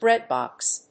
音節bréad・bòx 発音記号・読み方
/ˈbrɛˌdbɑks(米国英語), ˈbreˌdbɑ:ks(英国英語)/